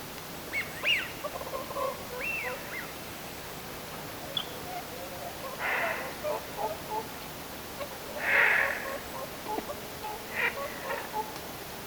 Lintulahdella oli edelleen lintuja.
Kuului yksi luhtakanan kip-ääni.
jälleen kuului luhtakanan kip-ääni
luhtakanan_aani.mp3